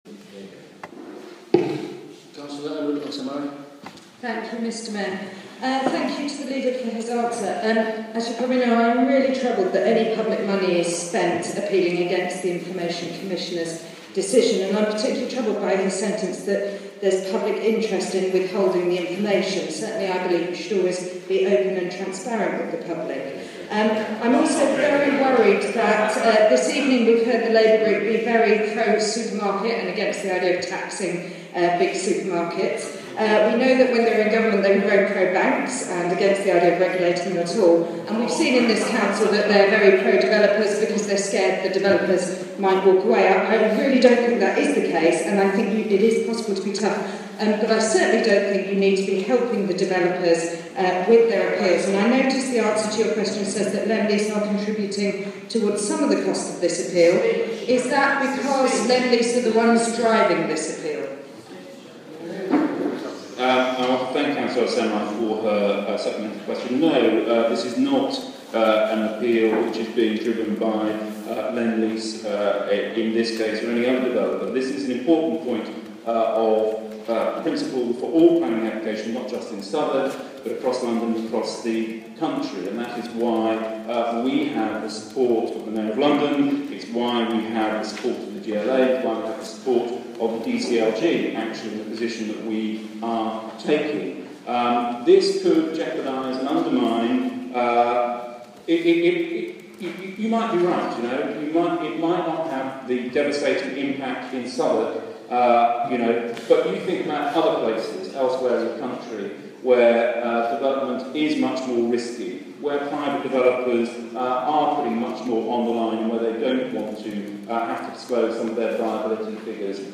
Supplementary question from Cllr Anood Al-Samerai to Cllr Peter John